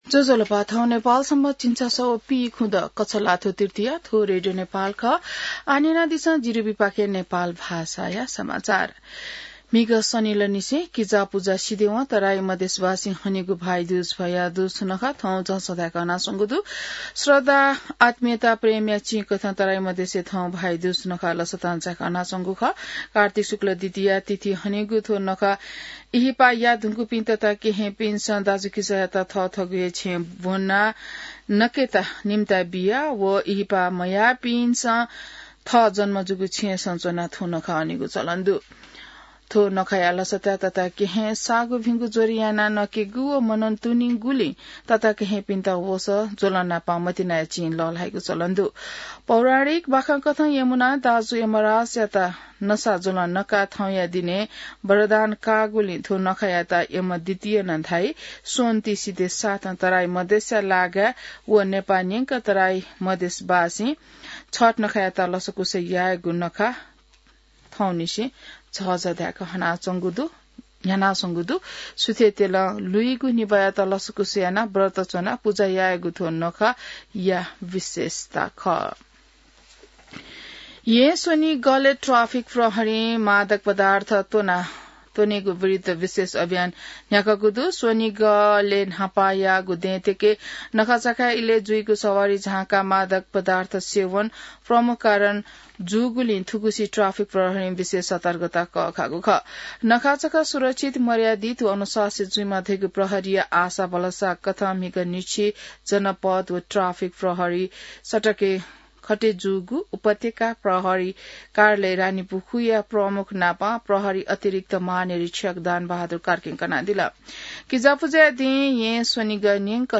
नेपाल भाषामा समाचार : ७ कार्तिक , २०८२